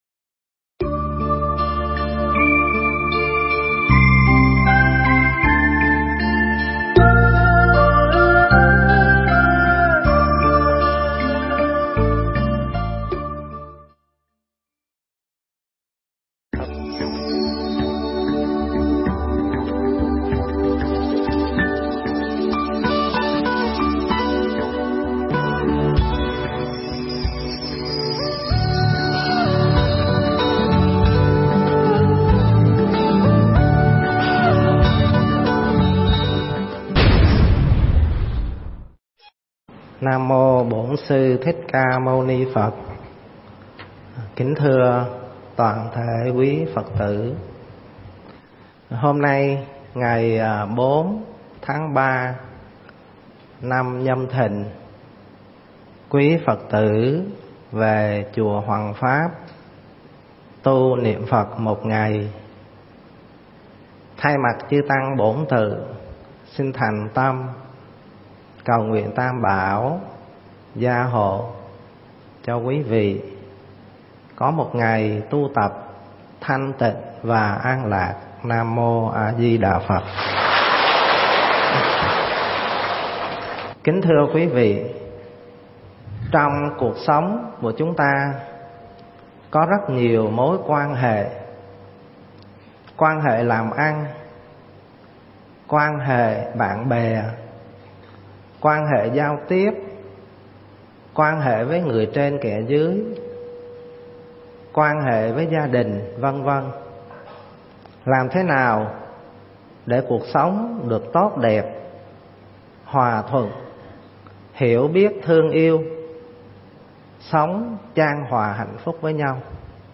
Nghe Mp3 thuyết pháp Người Được Ái Mộ